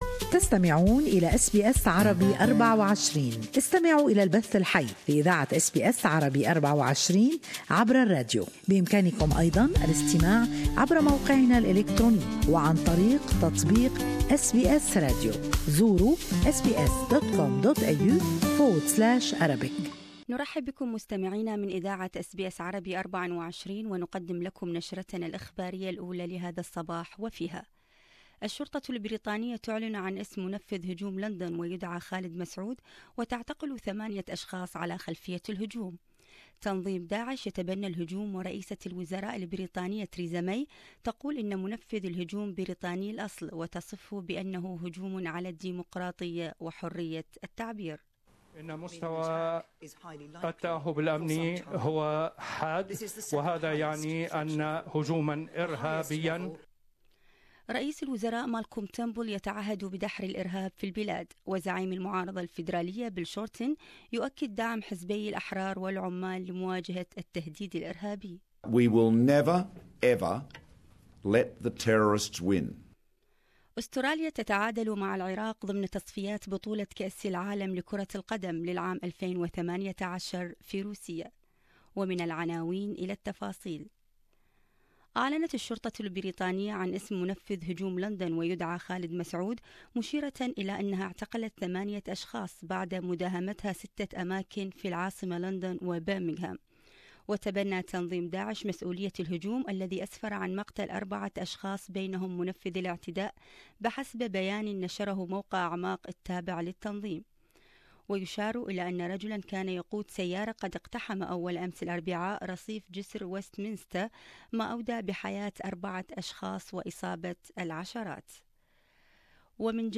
News Bulliten